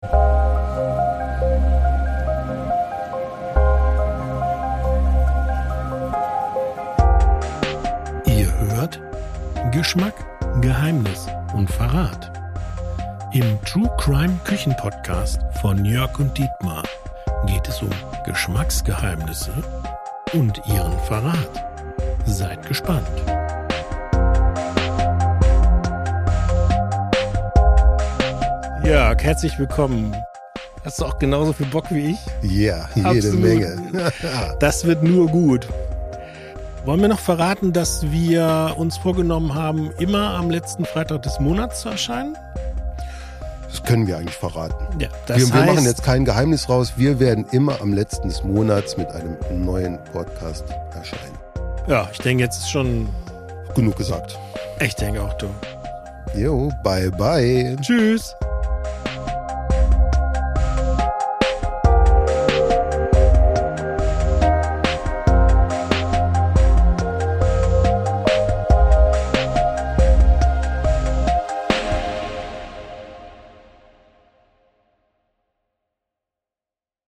Die True-Crime Kitchen Podcast-Show der Genussmanufaktur Trier
in diesem einzigartigen Podcast erwartet: Ein entspannter Talk